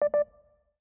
alert1.aif